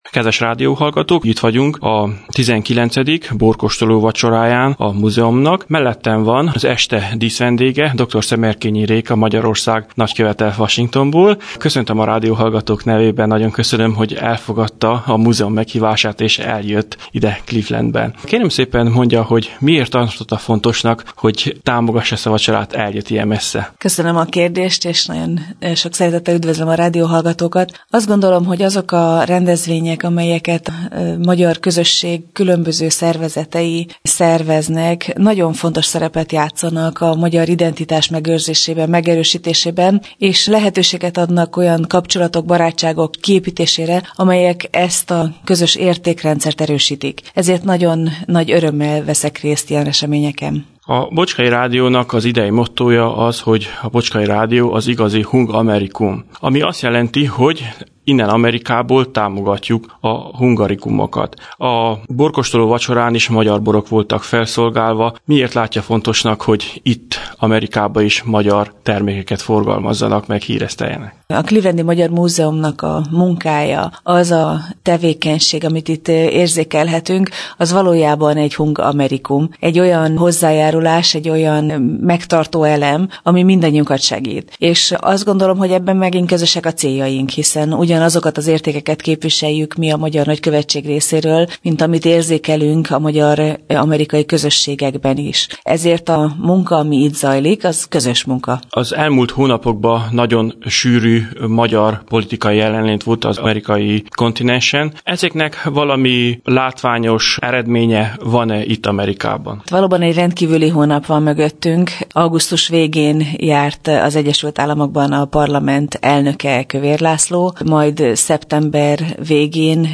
Természetesen készítettünk egy rövid interjút az est díszvendégével dr. Szemerkényi Réka Magyarország amerikai nagykövetasszonyával.